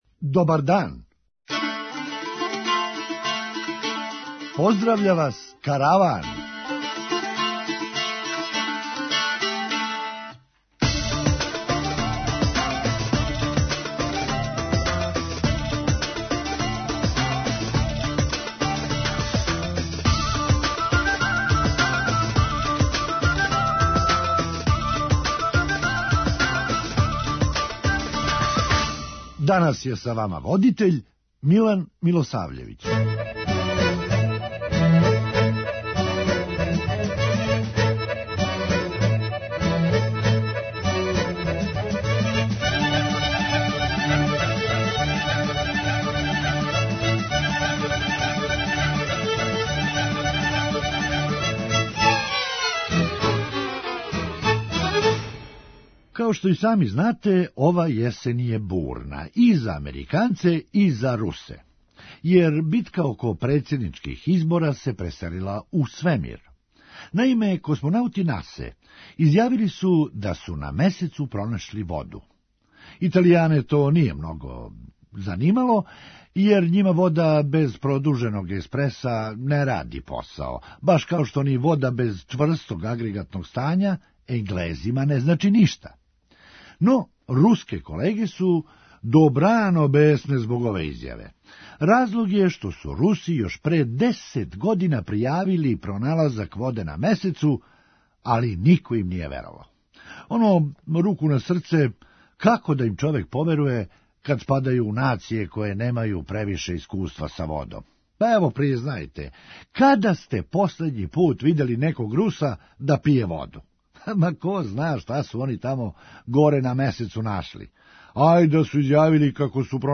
Хумористичка емисија
Што би рекли наши стари - Куд све војводе, ту и мали сердари! преузми : 9.62 MB Караван Autor: Забавна редакција Радио Бeограда 1 Караван се креће ка својој дестинацији већ више од 50 година, увек добро натоварен актуелним хумором и изворним народним песмама.